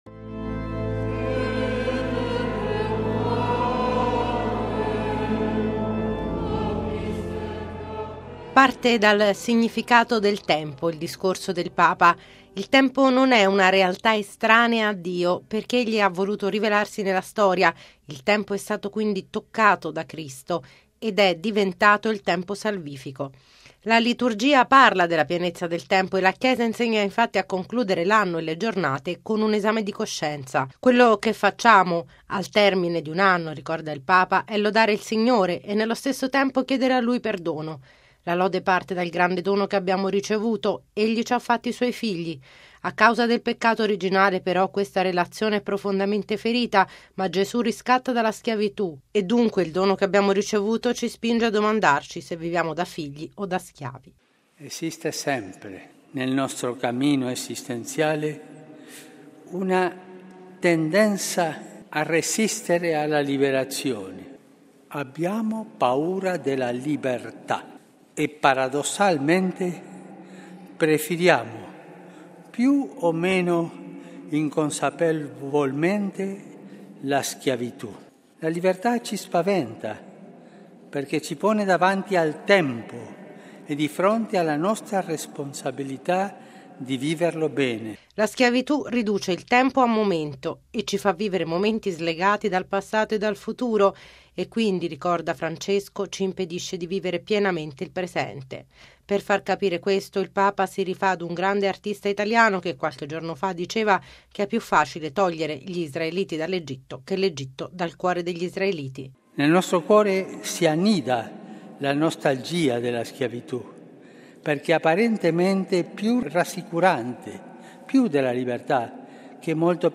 Quando una società ignora i poveri o li perseguita si impoverisce fino alla miseria. Così Papa Francesco durante i Primi Vespri della Solennità di Maria Santissima Madre di Dio, celebrati nella Basilica vaticana,  a cui è seguita l’esposizione del Santissimo Sacramento e l’inno del Te Deum in segno di ringraziamento al Signore per l’anno 2014.
Il servizio